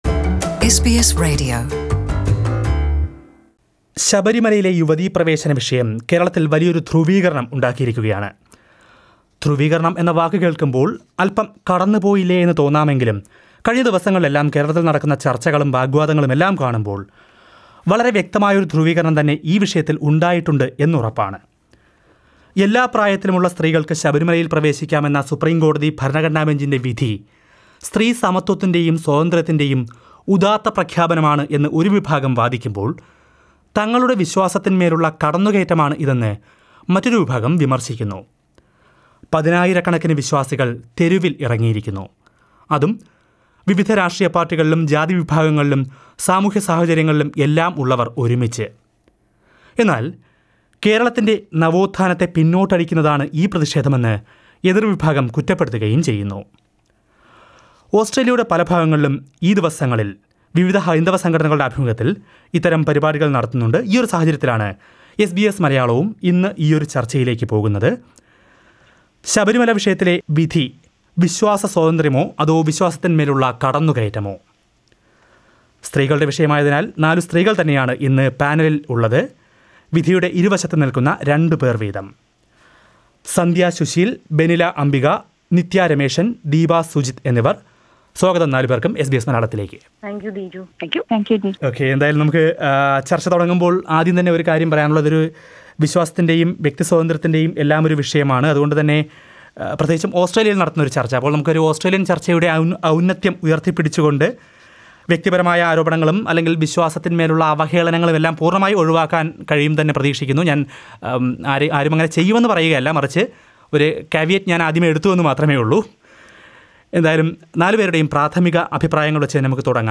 While the judgement of the Supreme Court of India allowing women entry to Sabarimala temple without any age restriction has led to huge protests and discussions in Kerala, SBS Malayalam debates the issue. Listen to four Malayalee women in Australia discussing various points in this issue.